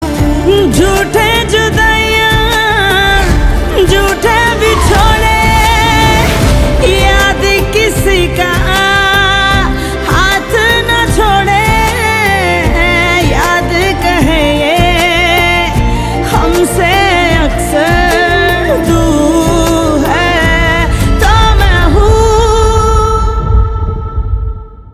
Categories: Bollywood Ringtones